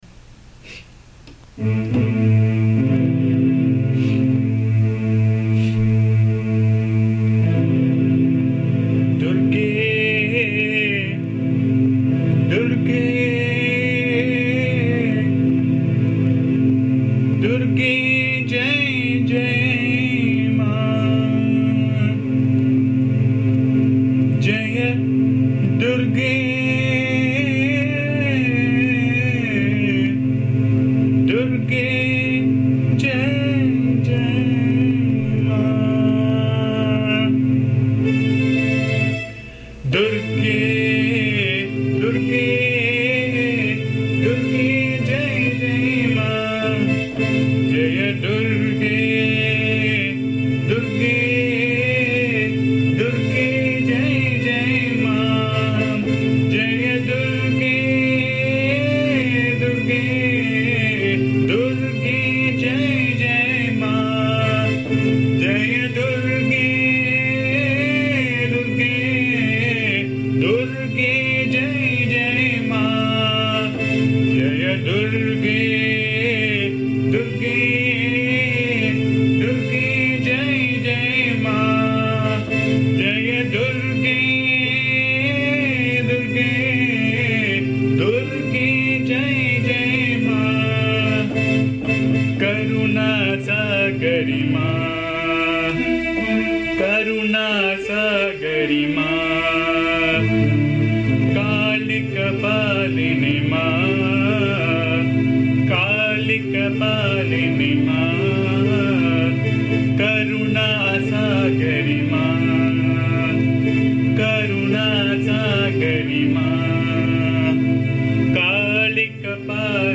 This is a small yet beautiful song praising Lord Durga. This song is generally sung as the last song in a bhajan. The song has been recorded in my voice which can be found here. Please bear the noise, disturbance and awful singing as am not a singer.